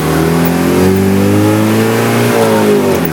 Index of /server/sound/vehicles/lwcars/uaz_452